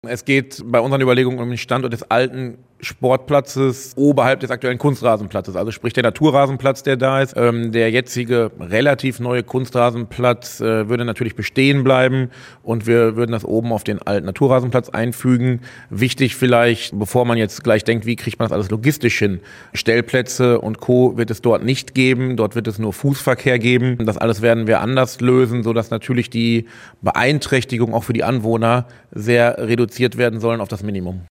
Oberbürgermeister Dennis Rehbein zur genauen Standortbeschreibung: